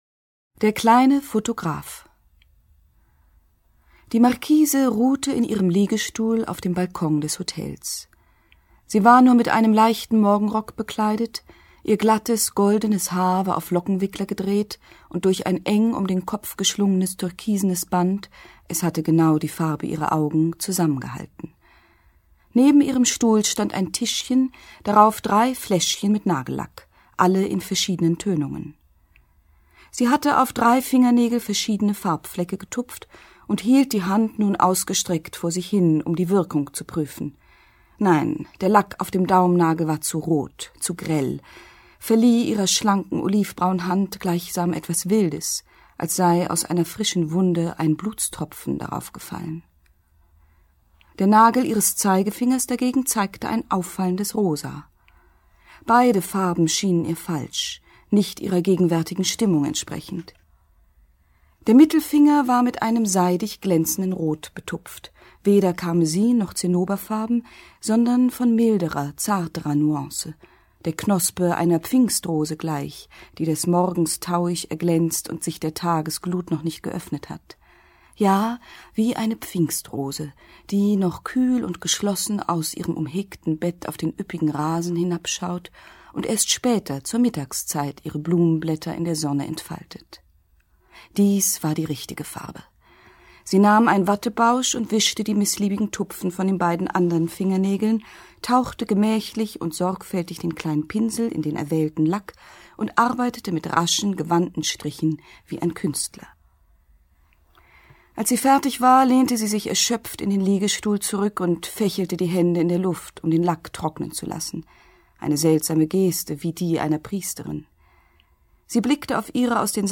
Lesung mit Imogen Kogge (1 mp3-CD)
Imogen Kogge (Sprecher)
»Hier wird fündig, wer an Hörbuchproduktionen Freude hat, die nicht schnell hingeschludert sind, sondern mit einer Regie-Idee zum Text vom und für den Rundfunk produziert sind.« NDR KULTUR